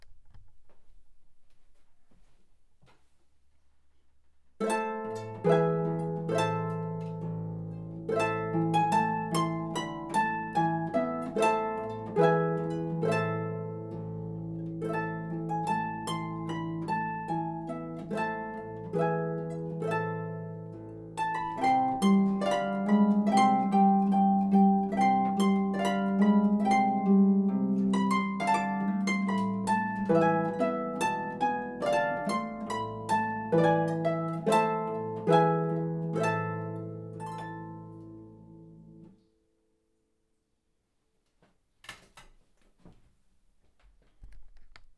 traditional Catalonian carol
solo pedal harp